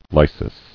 [ly·sis]